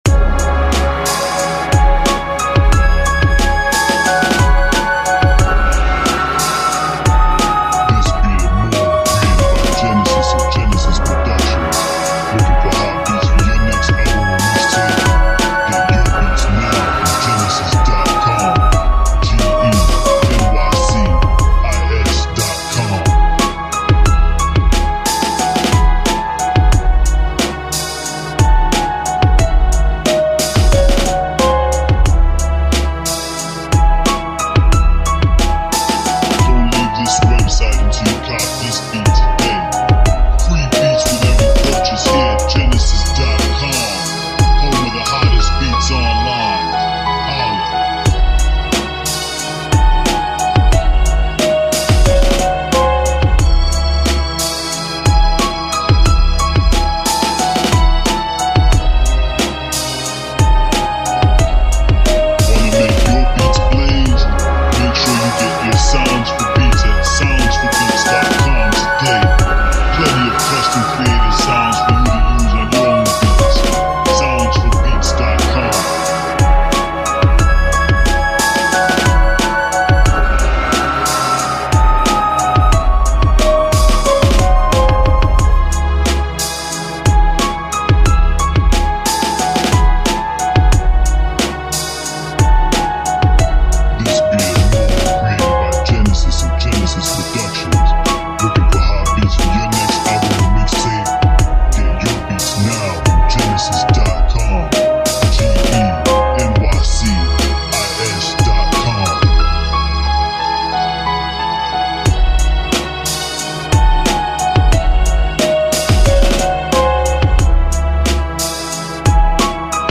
Storytelling Beats